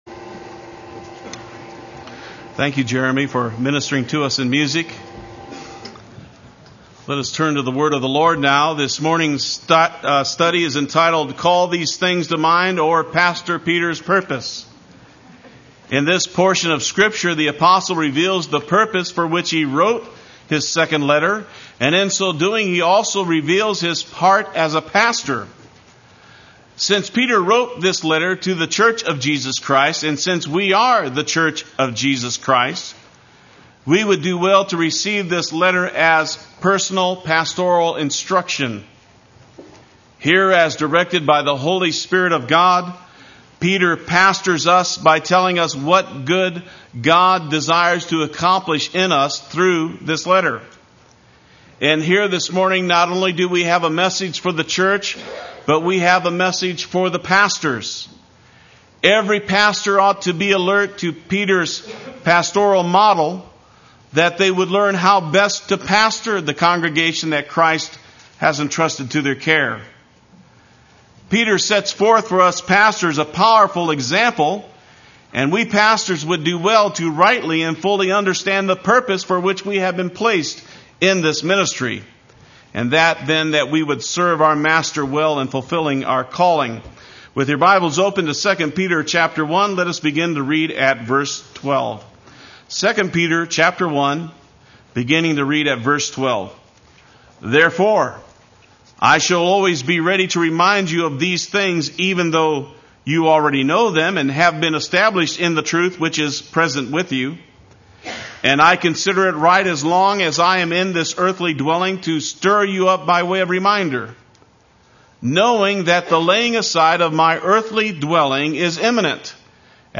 Play Sermon Get HCF Teaching Automatically.
Call These Things to Mind Sunday Worship